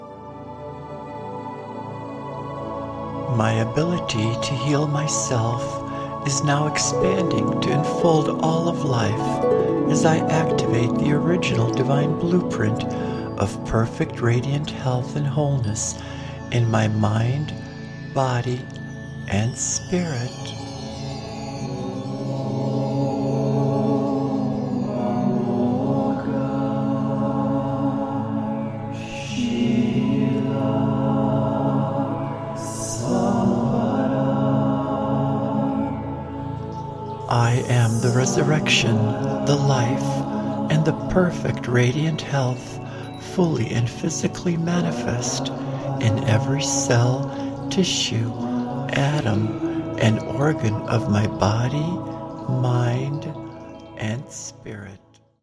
AN ADVANCED GUIDED MEDITATION